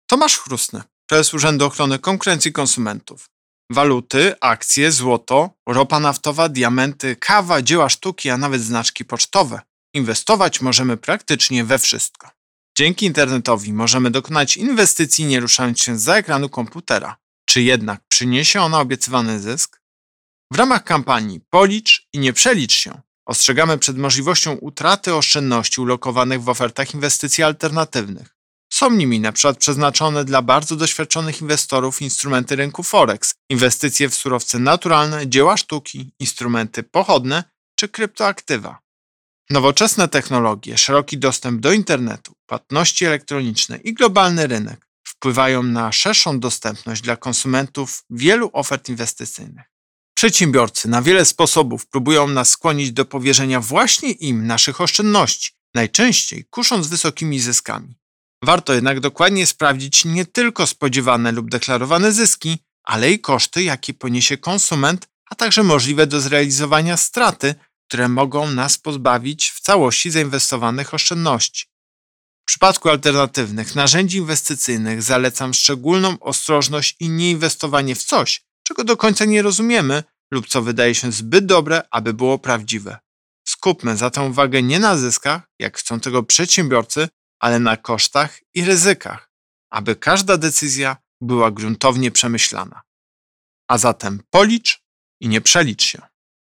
Wypowiedź Prezesa UOKiK Tomasza Chróstnego z 7 września 2021 r..mp3